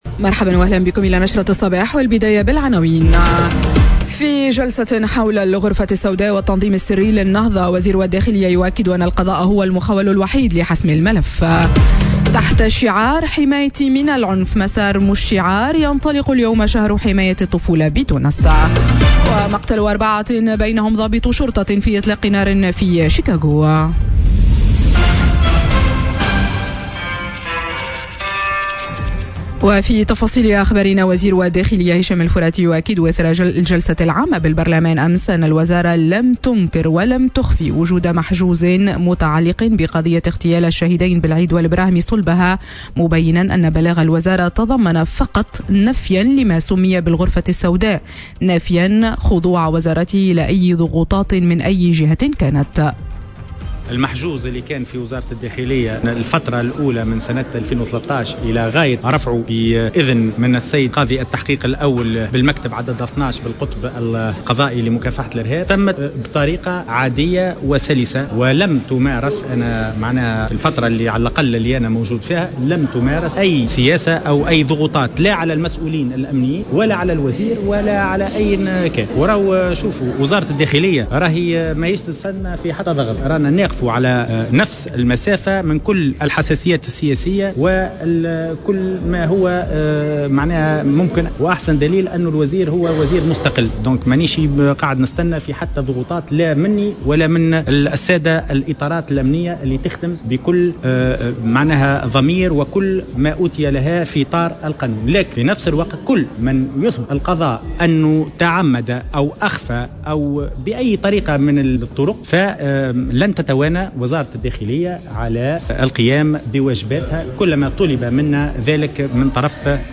نشرة أخبار السابعة صباحا ليوم الثلاثاء 20 نوفمبر 2018